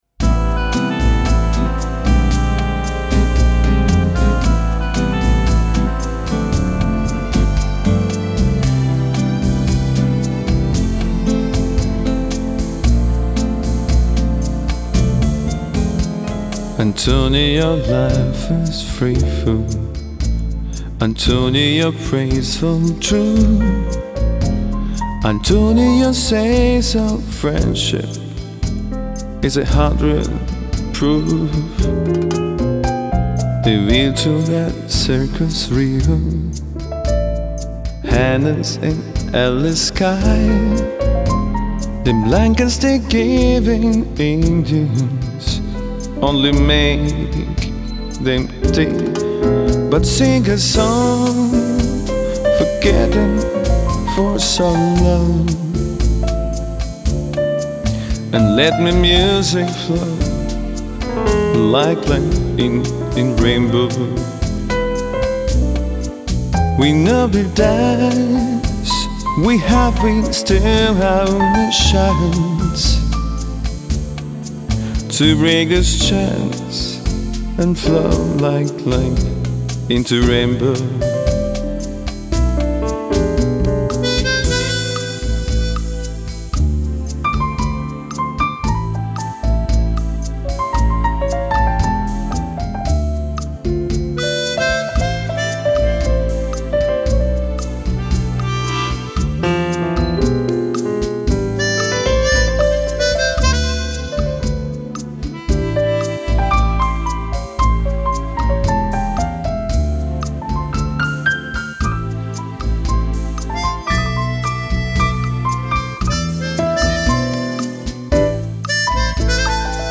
erstklassiger Pianist für Ihre Veranstaltung